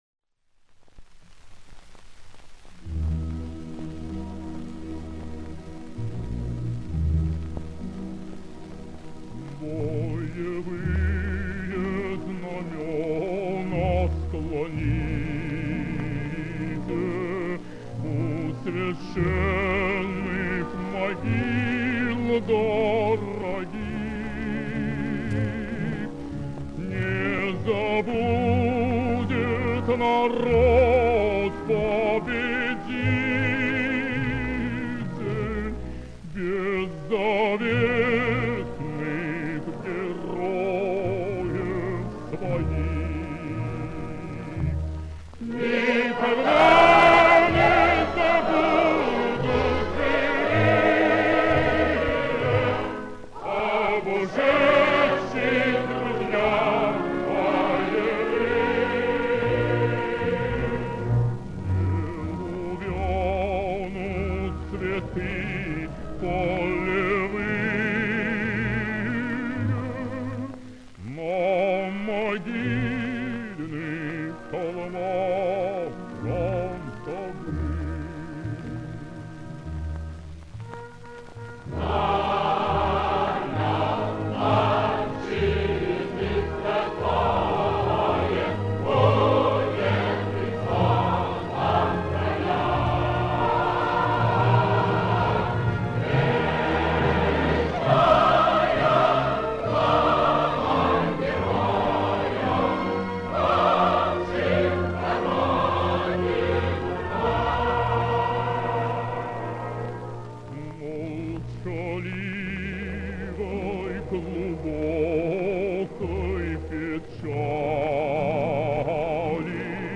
Повышение качества записи.